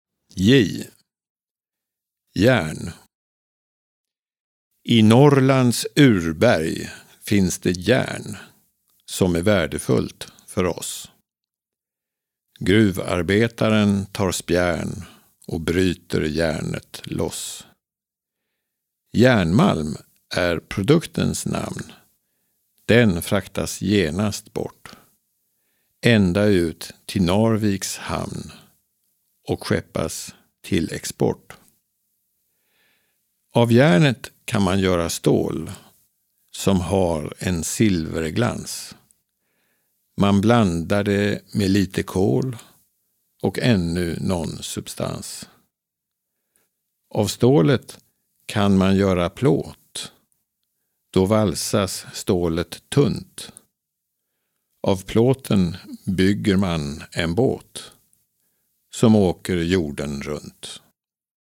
Här på webbplatsen kan man lyssna på ABC-verserna. Lyssna på långsammare inläsningar nedan: